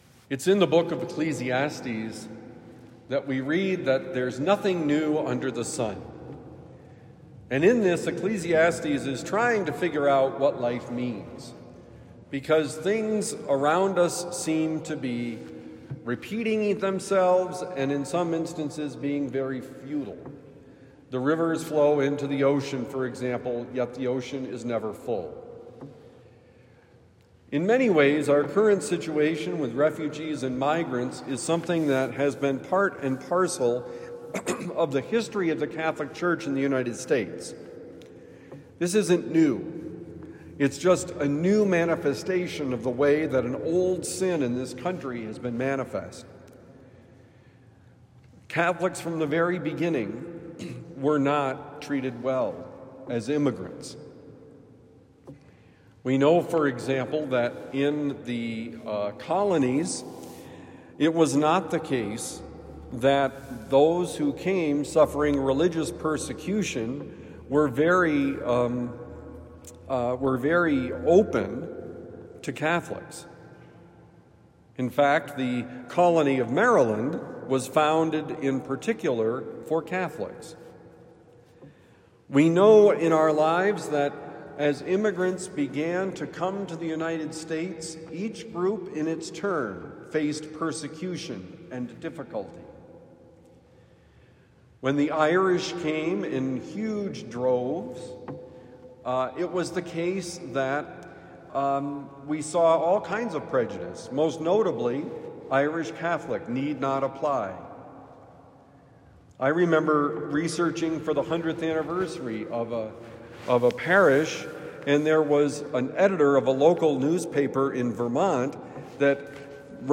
Nothing New: Homily for Thursday, November 13, 2025